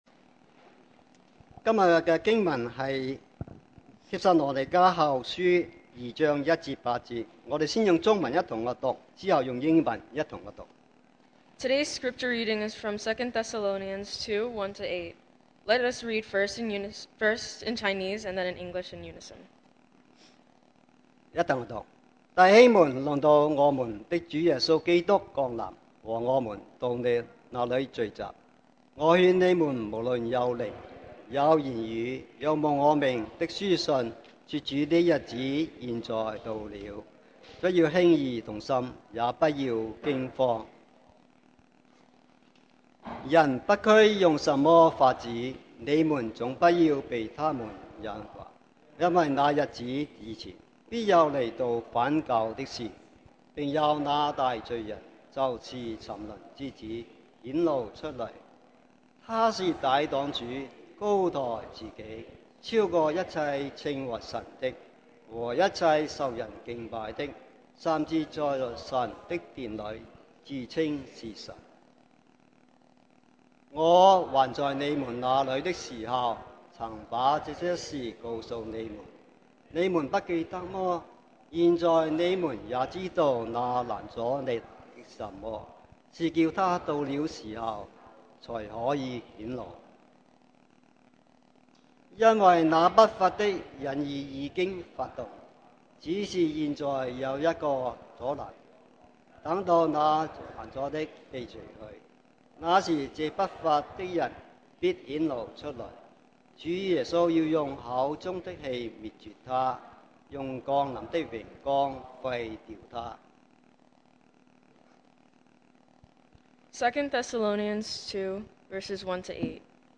2023 sermon audios 2023年講道重溫 Passage: 2 Thessalonians 2:1-8 Service Type: Sunday Morning Who’s to Say What Right and Wrong Is? 1 誰可以說什麼是對或者是錯？